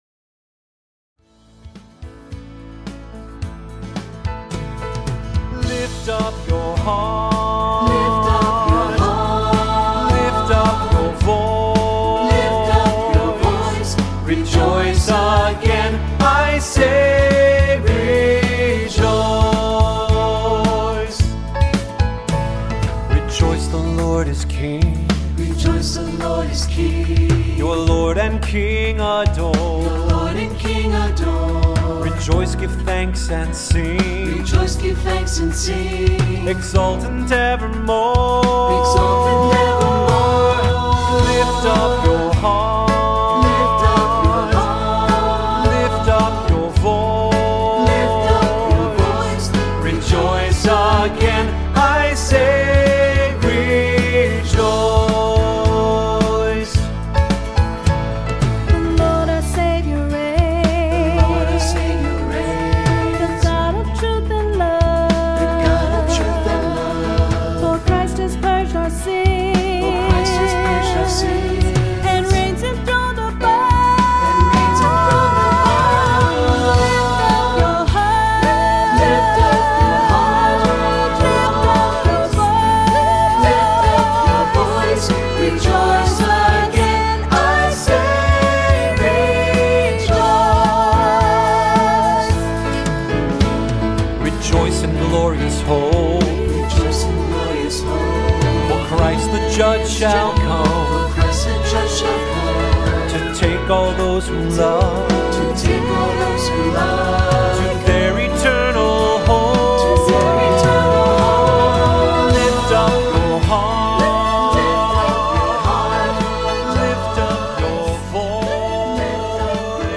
Voicing: Assembly, cantor, descant,SATB